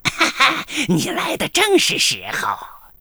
文件 文件历史 文件用途 全域文件用途 Gbn_tk_01.ogg （Ogg Vorbis声音文件，长度3.0秒，111 kbps，文件大小：41 KB） 源地址:游戏语音 文件历史 点击某个日期/时间查看对应时刻的文件。